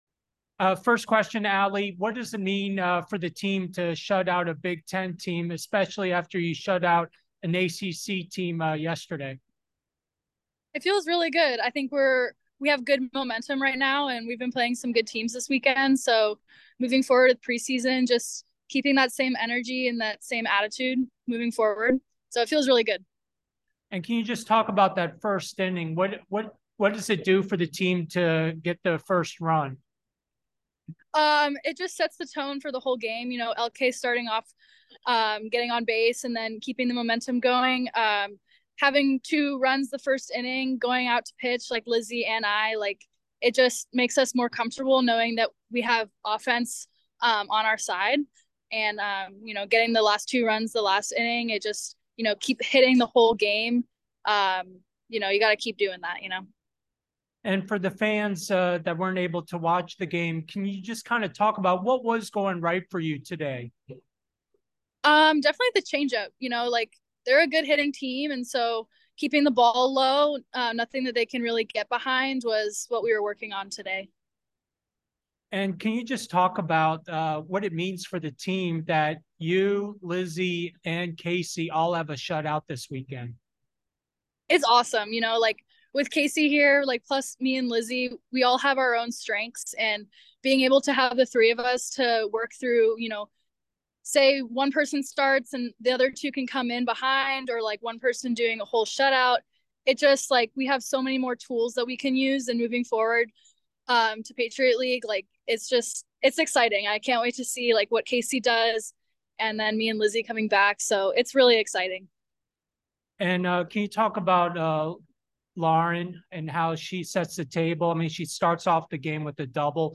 Penn State Postgame Interview